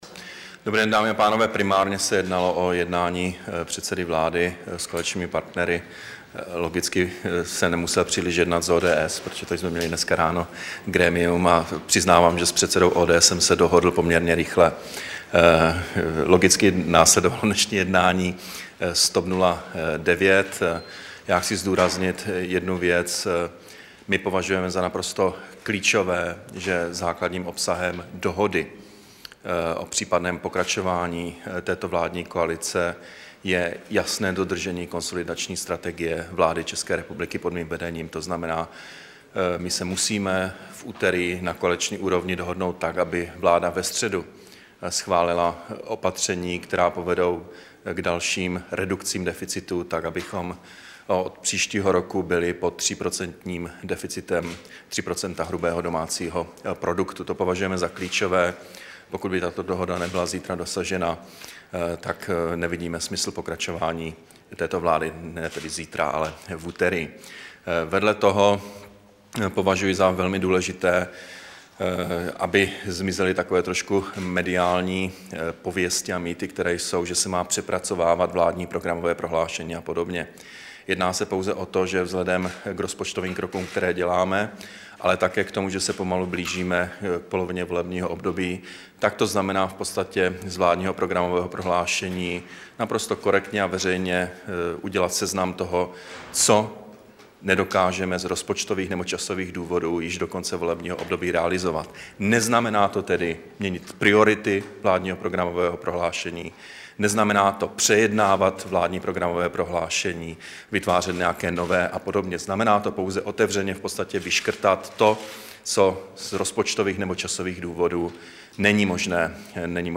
Brífink po jednání premiéra Nečase se zástupci TOP 09, 5. dubna 2012